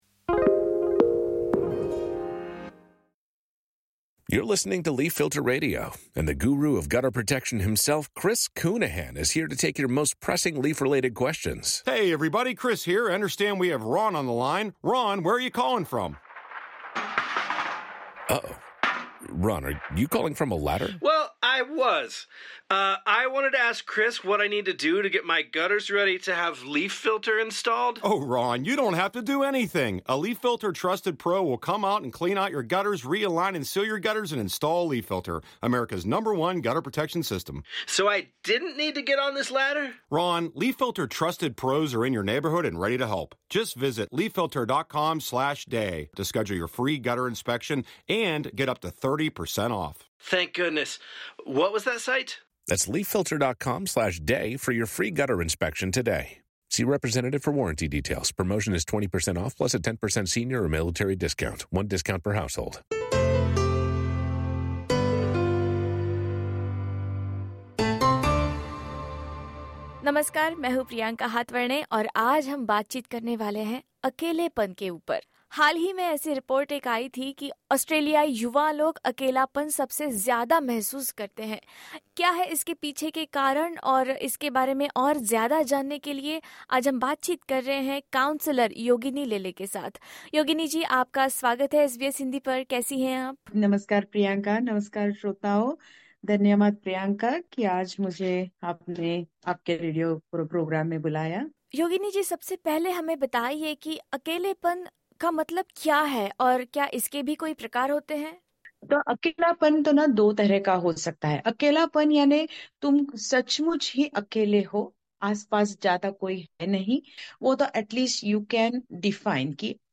मेन्टल हेल्थ एक्सपर्ट से सुनिए अकेलेपन से कैसे निपटे